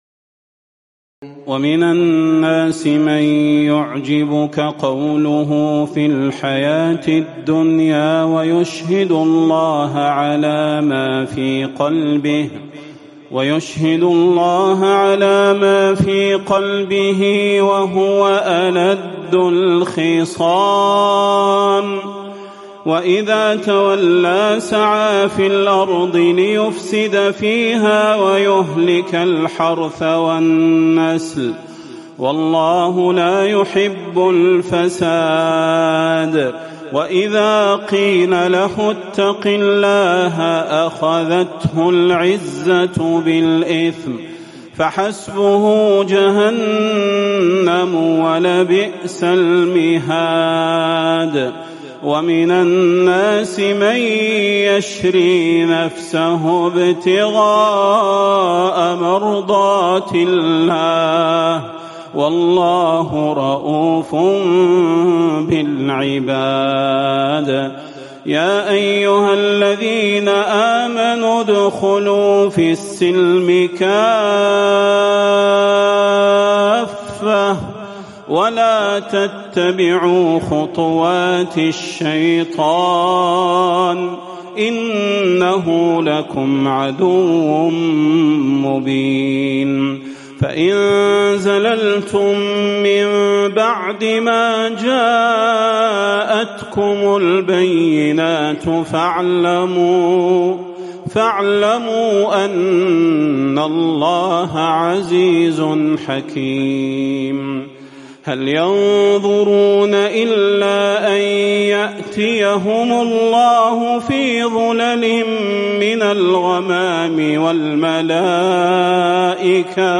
تراويح الليلة الثانية رمضان 1438هـ من سورة البقرة (204-252) Taraweeh 2 st night Ramadan 1438H from Surah Al-Baqara > تراويح الحرم النبوي عام 1438 🕌 > التراويح - تلاوات الحرمين